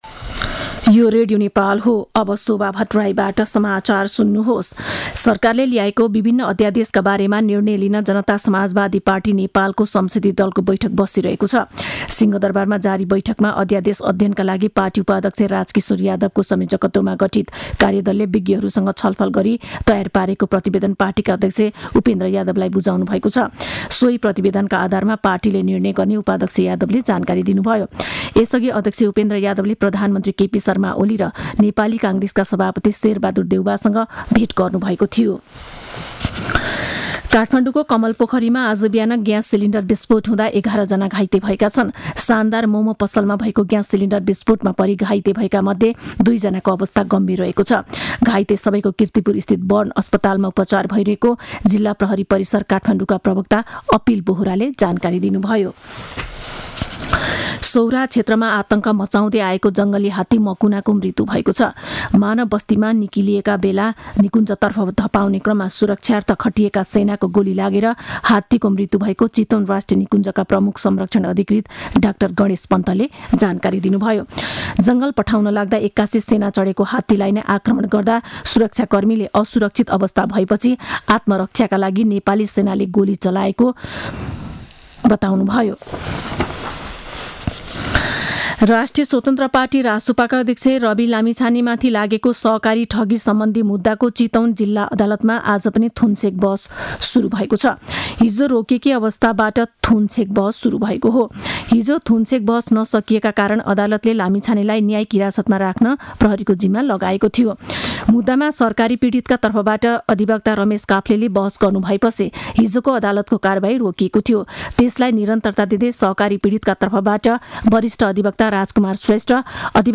दिउँसो १ बजेको नेपाली समाचार : २५ माघ , २०८१
1-pm-news-2.mp3